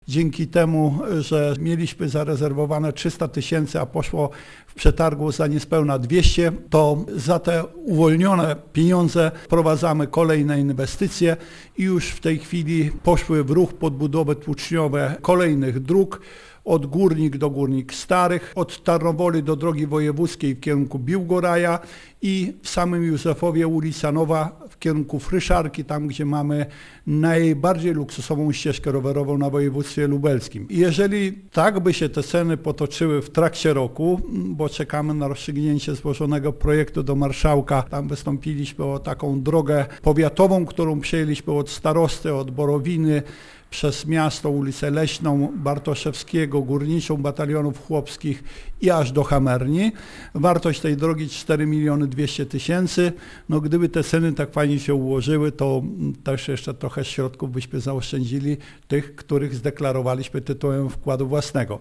Burmistrz Józefowa Roman Dziura przyznaje, że nie spodziewał się tak niskich kosztów robót, które ostatni raz można było wynegocjować na początku lat 90-tych minionego wieku: